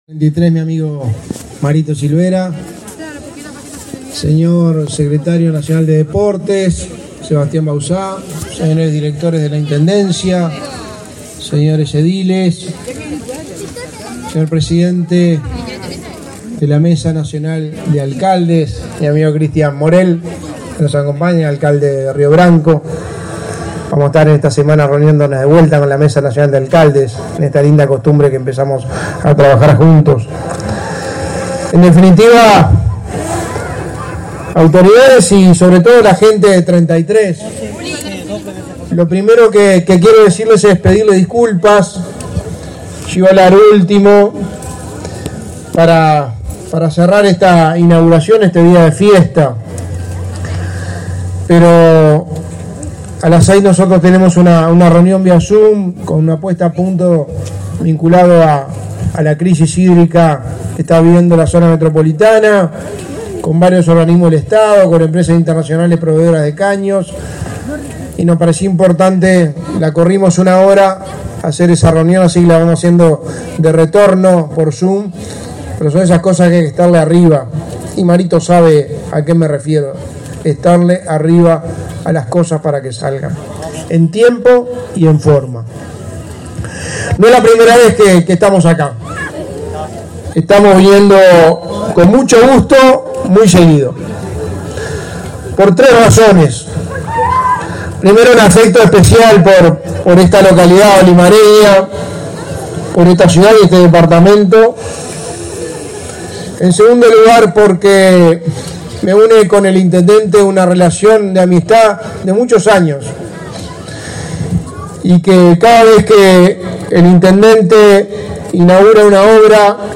Palabras del secretario de la Presidencia, Álvaro Delgado, en inauguración de obras en la ciudad de Treinta y Tres